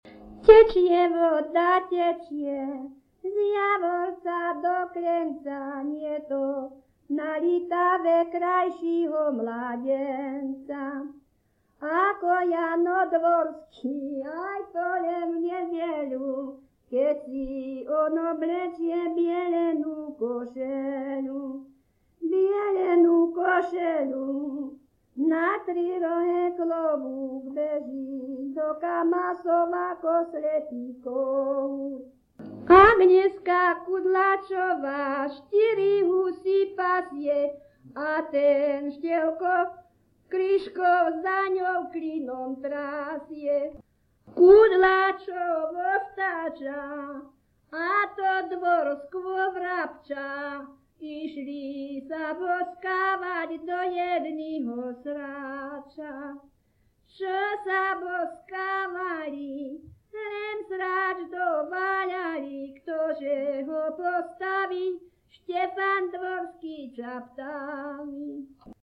Popis sólo ženský spev bez hudobného sprievodu
Miesto záznamu Litava
Kľúčové slová ľudová pieseň